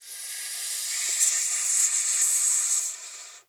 snake_2_hiss_slither_01.wav